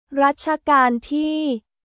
ラッチャカーン・ティー…